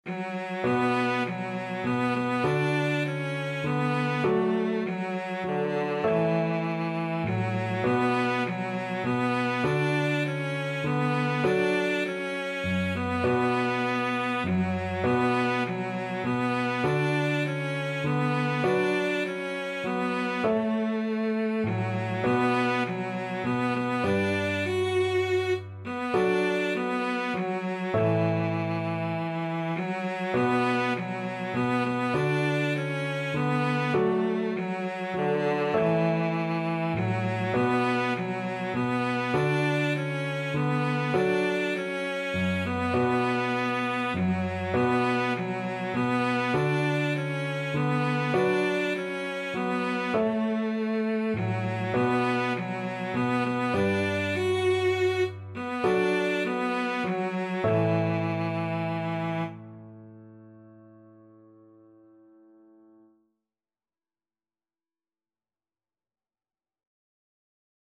Traditional Trad. I wonder as I wander (Appalacian carol) Cello version
D major (Sounding Pitch) (View more D major Music for Cello )
6/8 (View more 6/8 Music)
Traditional (View more Traditional Cello Music)